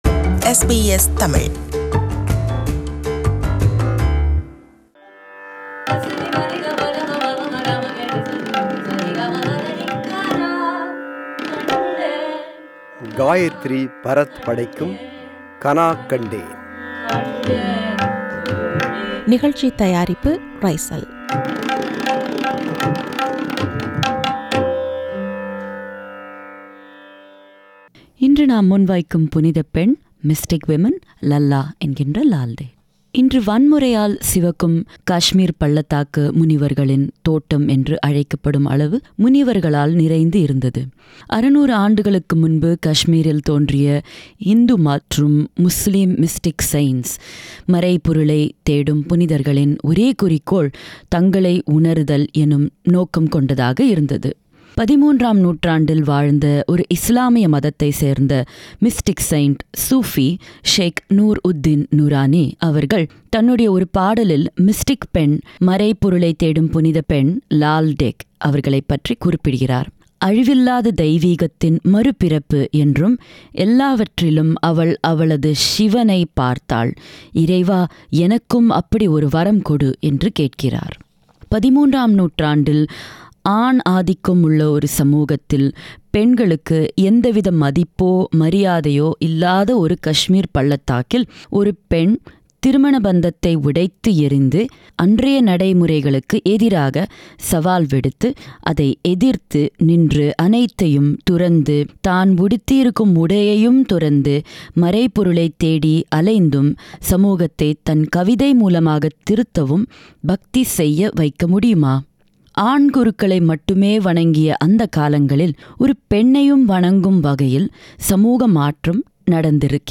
Lalleshwari, locally known mostly as Lal Ded, was a Kashmiri mystic of the Kashmir Shaivism school of philosophy in the Indian subcontinent. Harmonium
Tabla
Mridangam
Tanpura
Studio: SBS